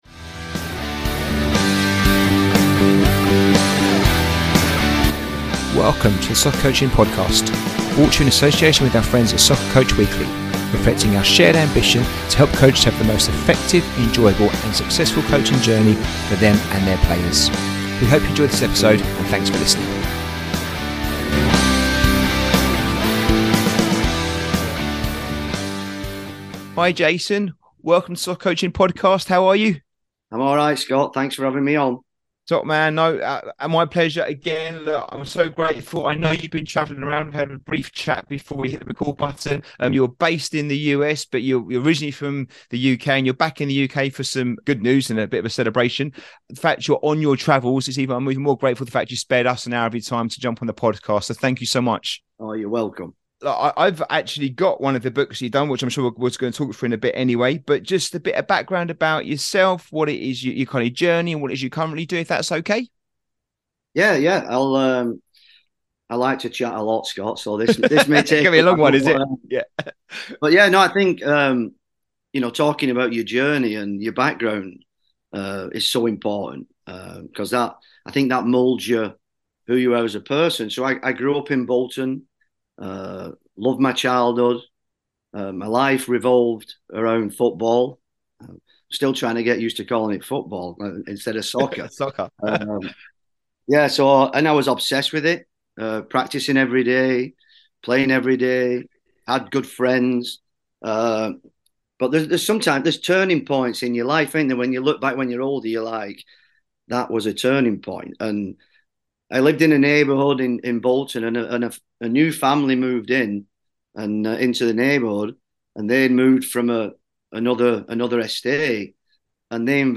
The Soccer Coaching Podcast